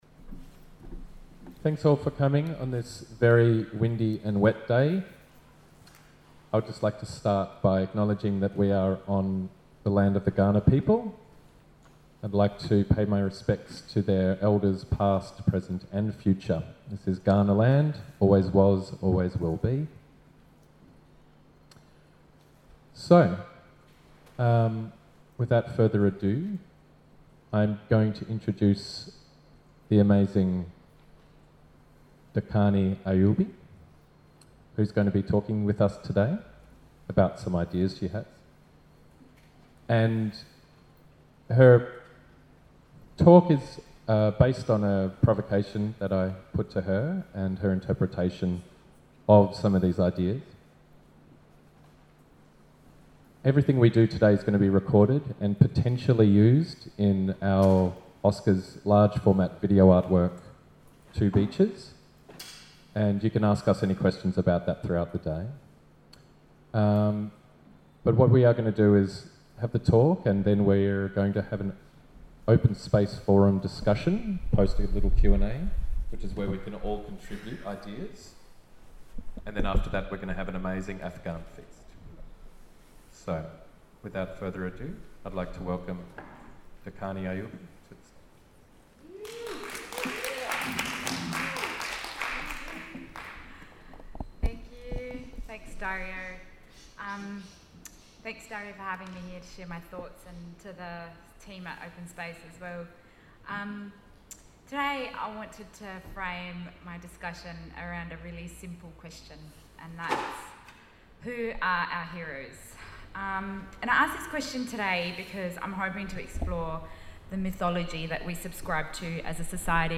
2Beaches Forum 2016 – Keynote Speech
2b_forum_keynote_01.mp3